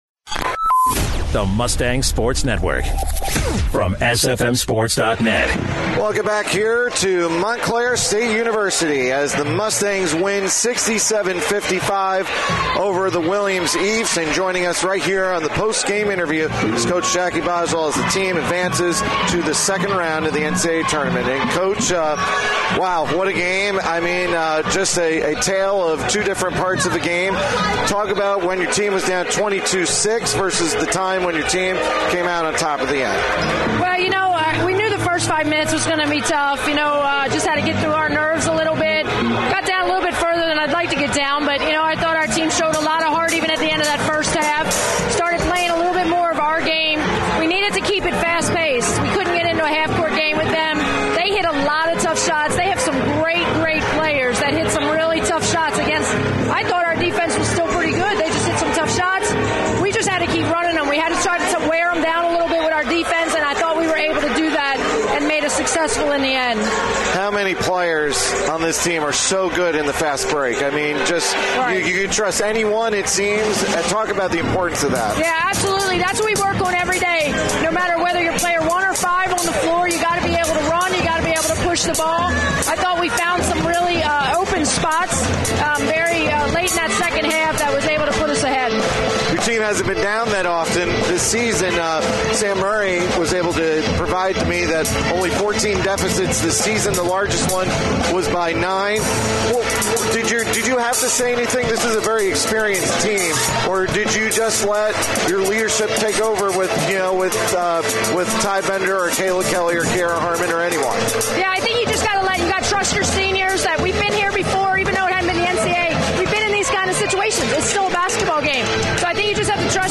3/6/15: Stevenson Women's Basketball Post Game Show